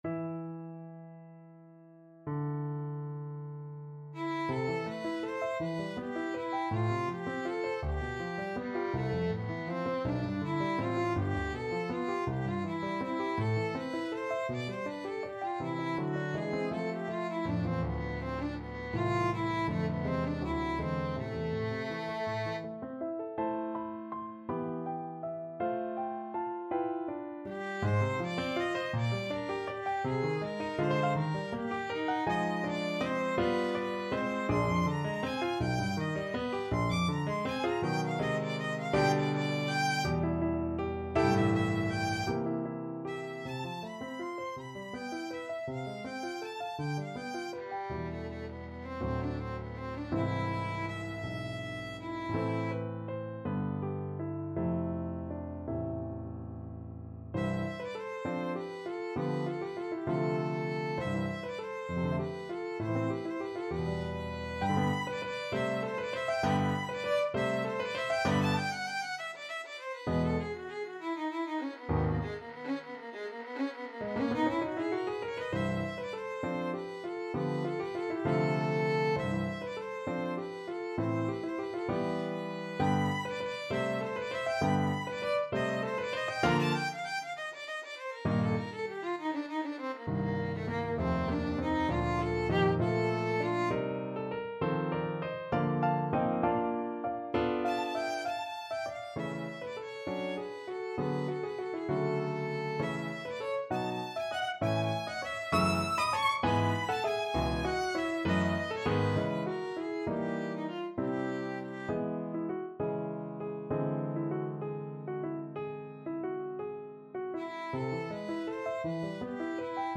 6/8 (View more 6/8 Music)
Classical (View more Classical Violin Music)